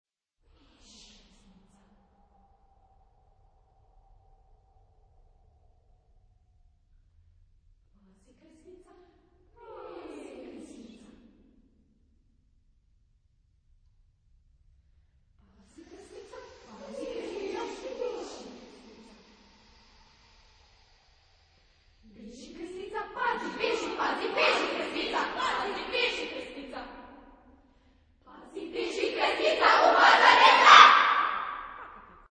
Genre-Style-Forme : Suite ; contemporain ; Profane
Caractère de la pièce : mystique
Solistes : Soloist group  (6 soliste(s))